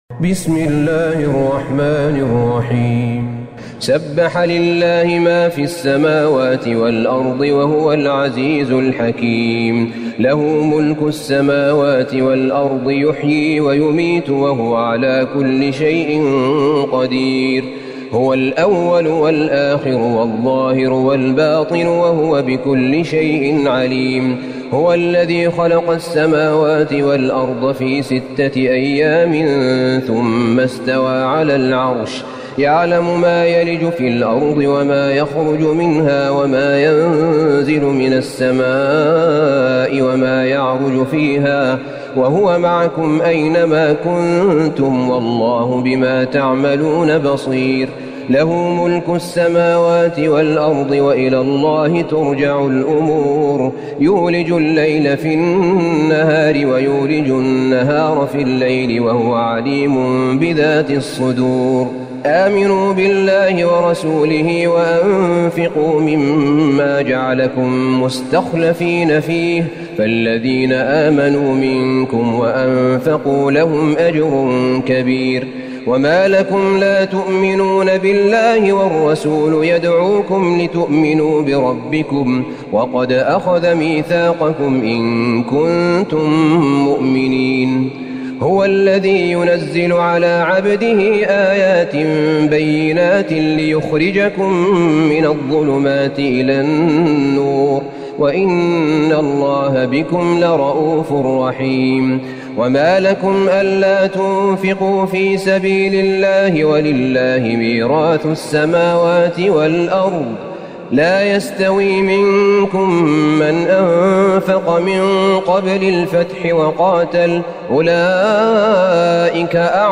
سورة الحديد Surat Al-Hadid > مصحف الشيخ أحمد بن طالب بن حميد من الحرم النبوي > المصحف - تلاوات الحرمين